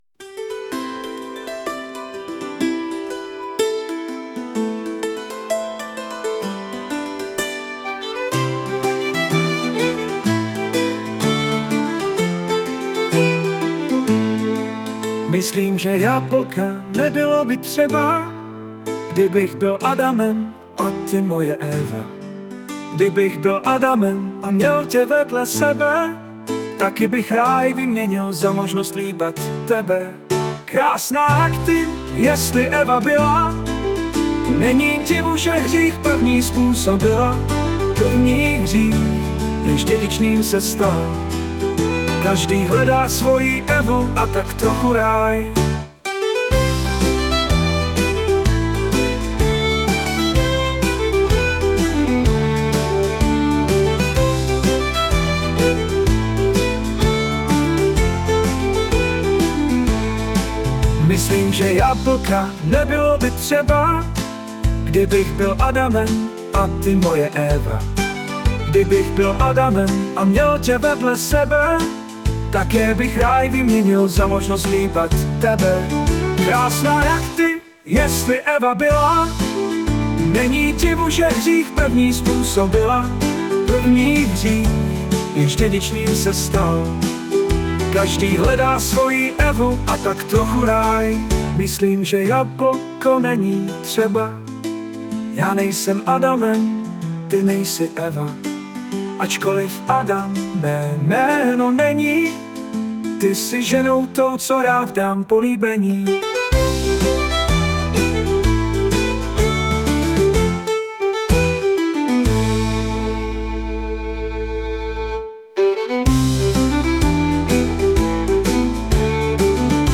* hudba, zpěv, obraz: AI